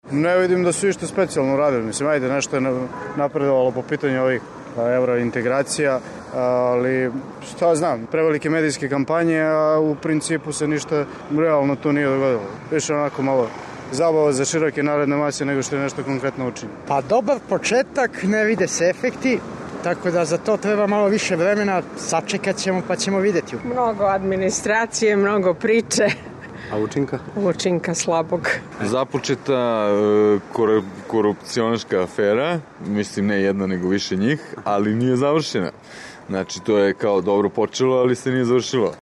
Dok Srbija iza sebe ostavlja još jednu burnu godinu, građane pitamo šta je ono što su državni čelnici u njoj postigli, a šta im zameraju:
Građani o radu Vlade